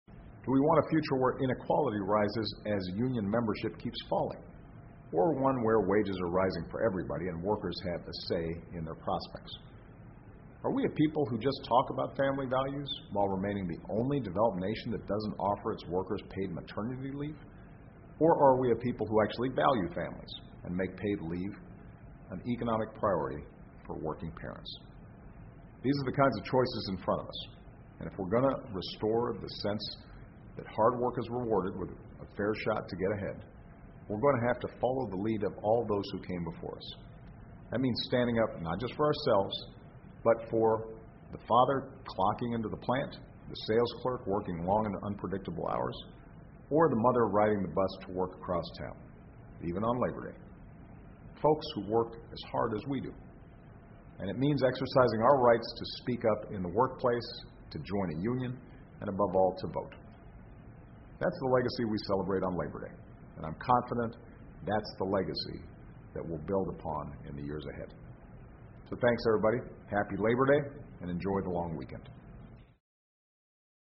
奥巴马每周电视讲话：总统阐释劳工节传承的财富（03） 听力文件下载—在线英语听力室